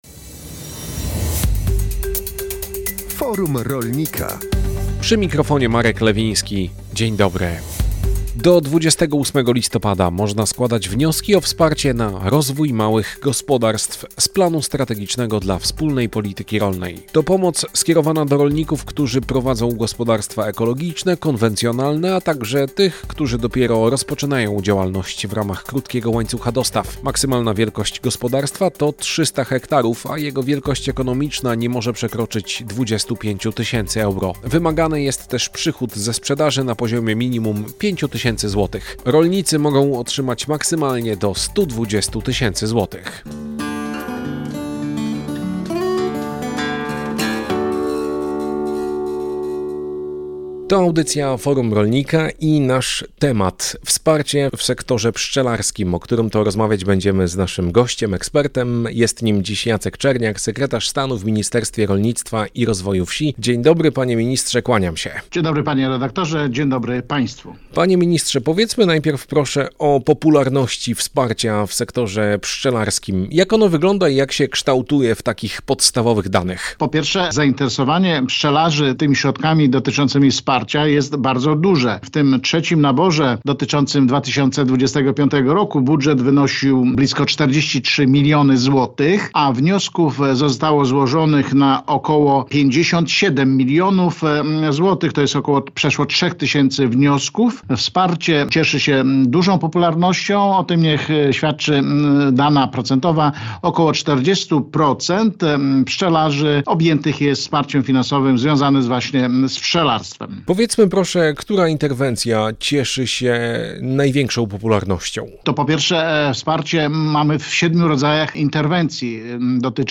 Audycja o tematyce rolnej „Forum Rolnika” emitowana jest na antenie Radia Kielce w środy po godz. 12.